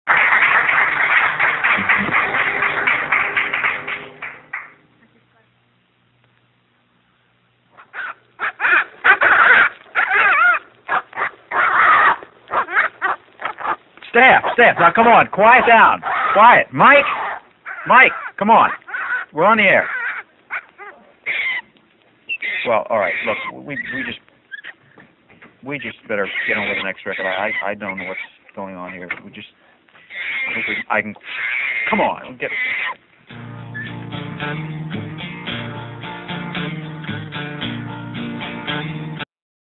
ends with applause